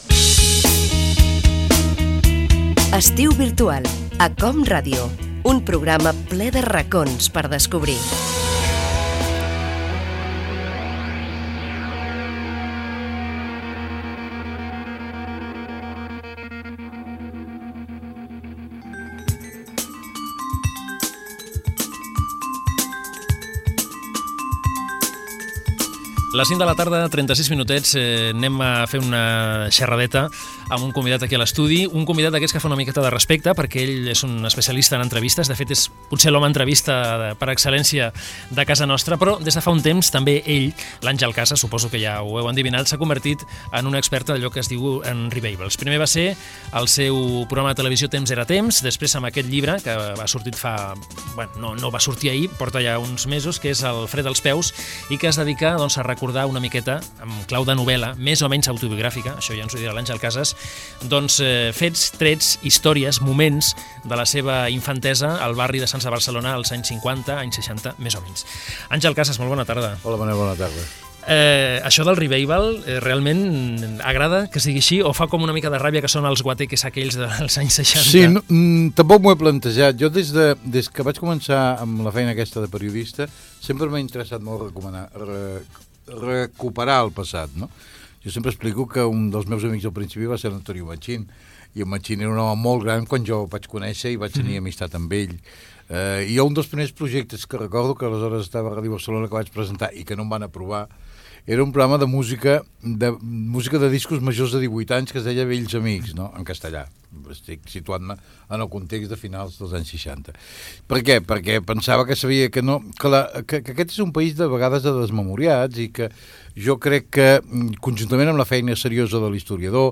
Indicatiu del programa, entrevista al periodista Àngel Casas, que ha publicat el llibre "Fred als peus"
Entreteniment